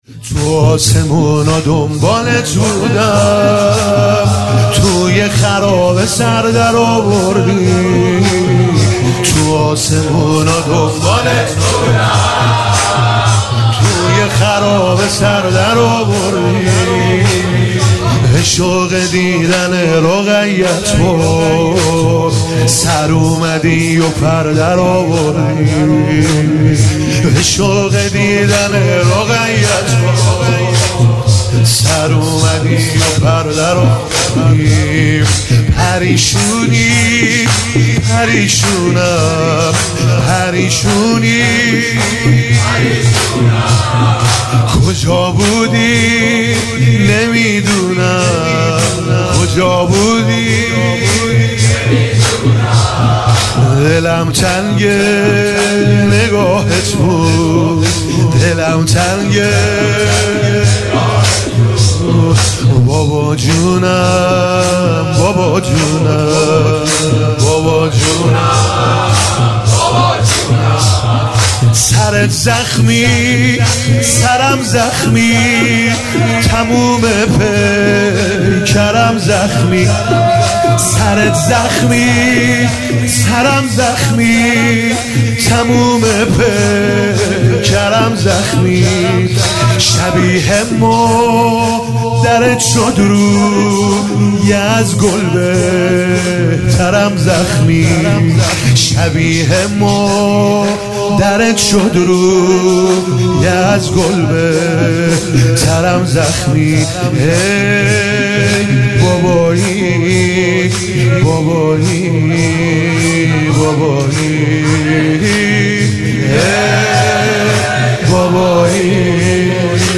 عنوان شب سیزدهم صفر ۱۳۹۹